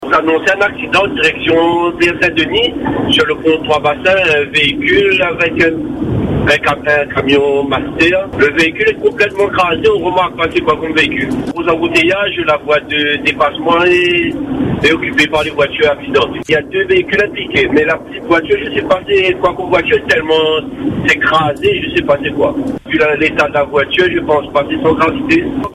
Selon le témoin, qui a appelé la radio en direct, il était impossible de donner la marque de la petite voiture tant elle est réformée… « les dégâts matériels sont importants, on a appelé les secours, mais je crains que le conducteur ou la conductrice ne soit grièvement blessé à l’intérieur au vu de l’état du véhicule » annonçait notre auditeur.